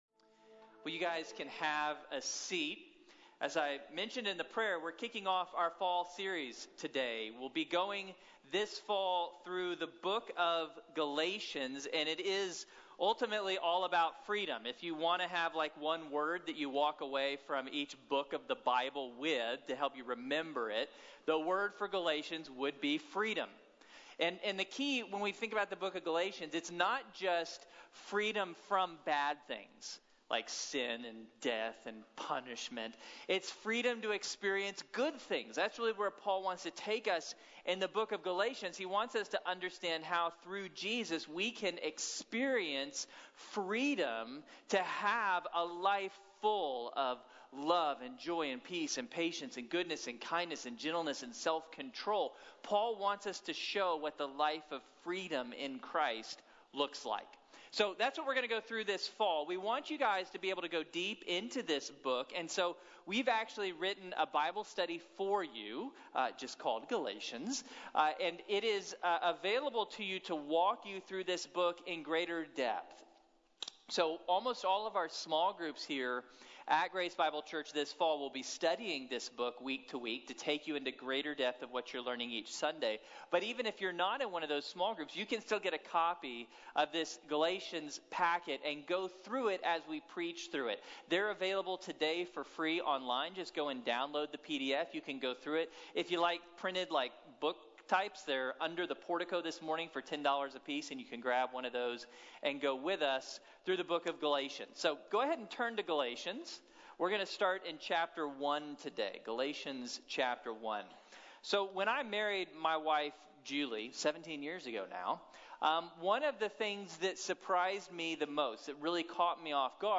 Freedom through the Gospel | Sermon | Grace Bible Church